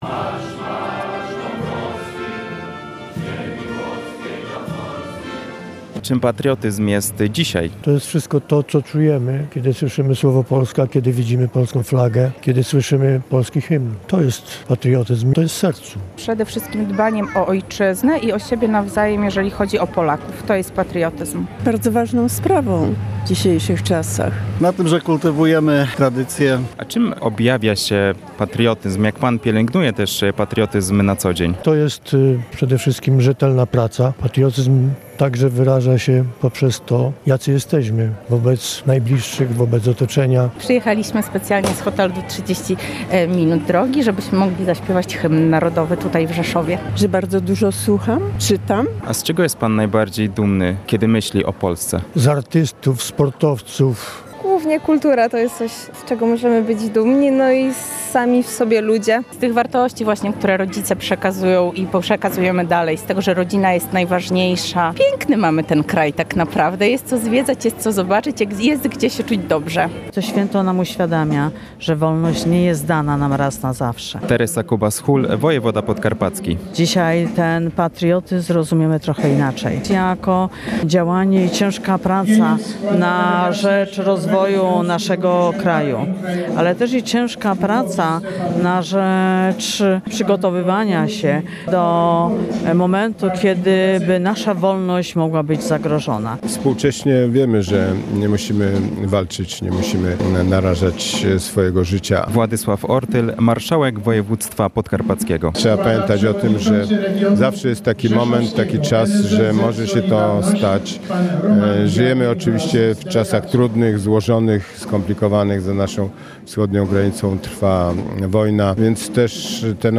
Na rzeszowskim Rynku odbyły się obchody związane z ustanowieniem konstytucji 3 maja.
Obecna na uroczystościach, Wojewoda Podkarpacki Teresa Kubas-Hul, mówiła o tym, czym jest patriotyzm.